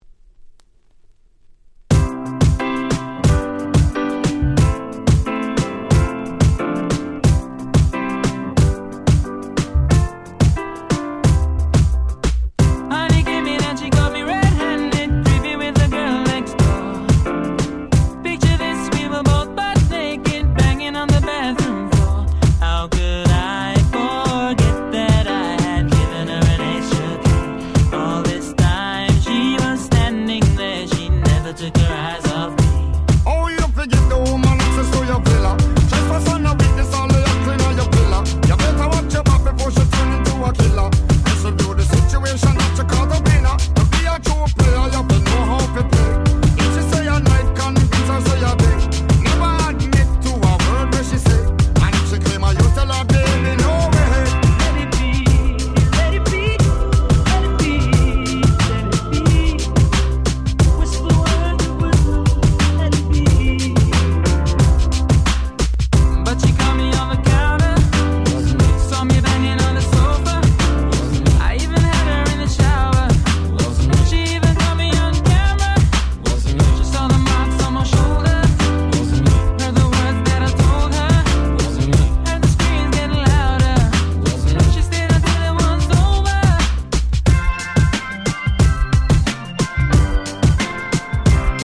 夏ソング アゲアゲ EDM レゲエ Reggae 00's R&B